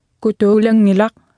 Speech Synthesis Martha